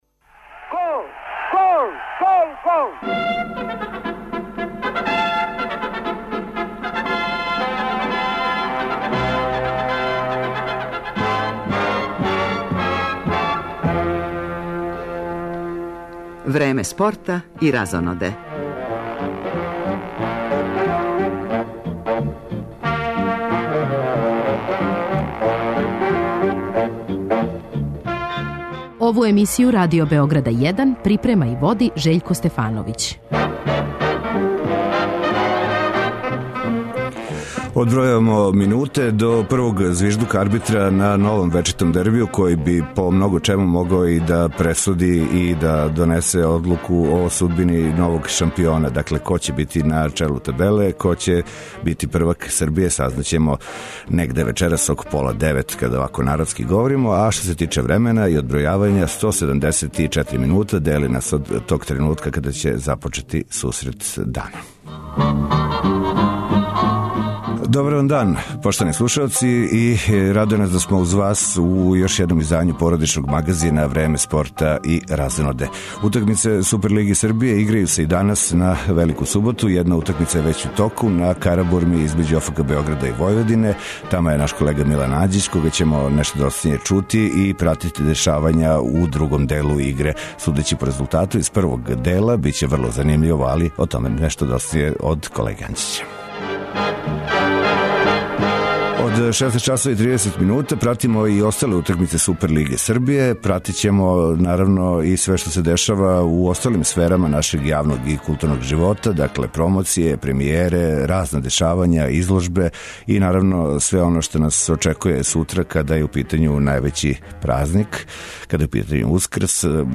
У првом делу слушаћемо укључења са утакмице ОФК Београд - Војводина, по њеном завршетку и са осталих првенствених сусрета, а од 18.30 пратимо директан пренос дербија овог кола и шампионата Србије, утакмицу између Партизана и Црвене Звезде. Током поподнева, извештаваћемо и о резултатима Прве лиге Србије, као и немачког и енглеског првенства.